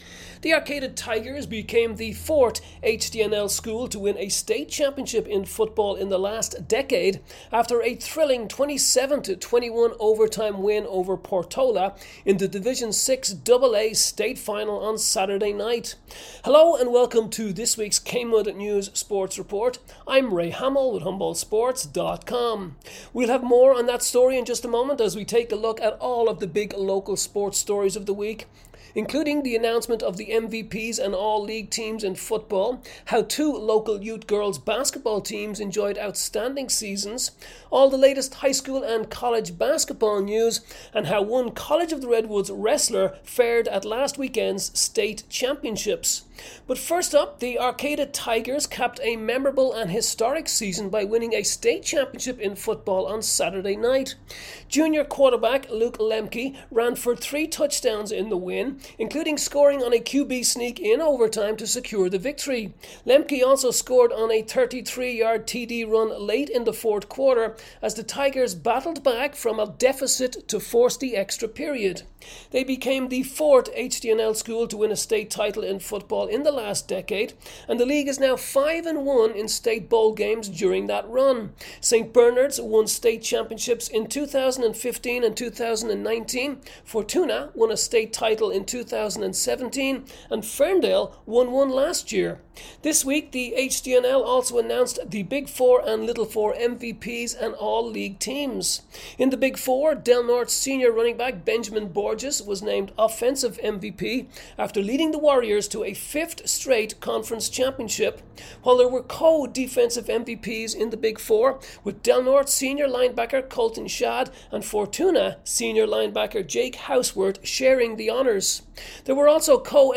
Dec 19 KMUD News sports report